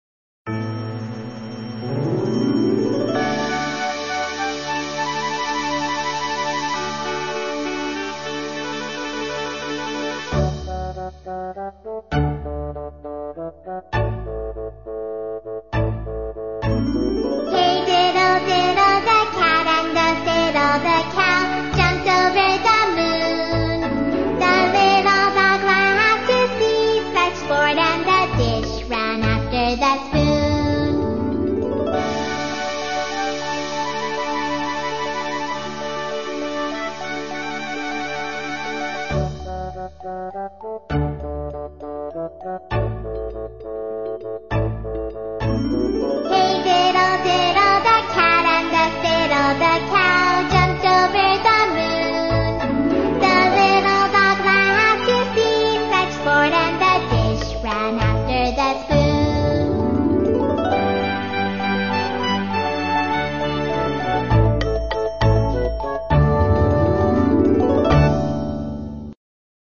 在线英语听力室英语儿歌274首 第64期:Hey,Diddle,Diddle的听力文件下载,收录了274首发音地道纯正，音乐节奏活泼动人的英文儿歌，从小培养对英语的爱好，为以后萌娃学习更多的英语知识，打下坚实的基础。